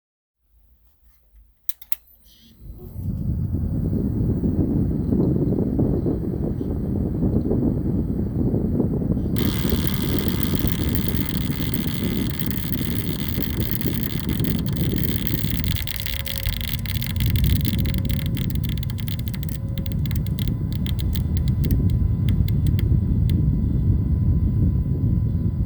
All-in-One - MSI 240 mag core macht geräusche
Hallo liebe Wasserkühlungs Experten, ich habe mir vor fast nen halben Jahr eine msi 240 mag core gekauft und diese macht seit 1-2 Monaten Geräusche, als würde luft im System sein, vorher trat das Geräusch nur ganz kurz und nur beim Starten des pc's auf, mittlerweile hält dies aber dauerhaft an...
das Geräusch kommt aich so ziemlich aus der umgebung der pumpe. Ich hoffe ihr könnt mir helfen Anhänge Wasserkühlung_Geräusch.mp3 Wasserkühlung_Geräusch.mp3 439,1 KB